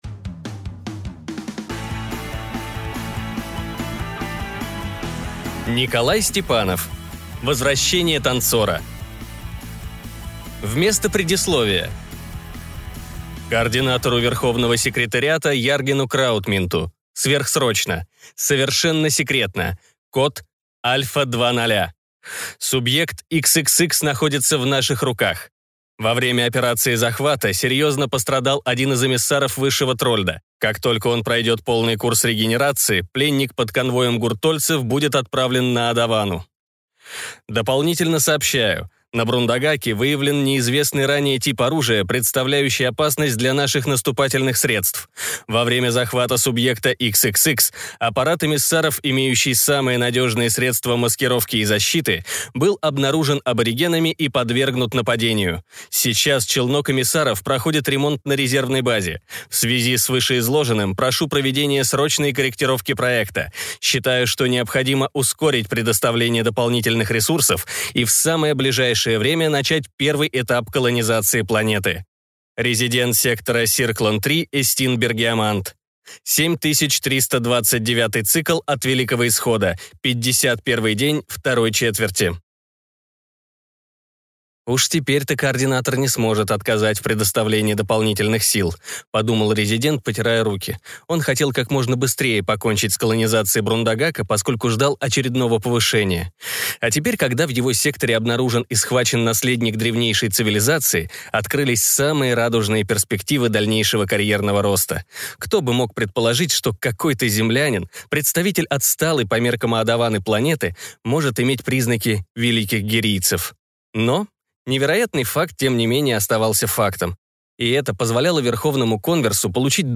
Аудиокнига Возвращение Танцора | Библиотека аудиокниг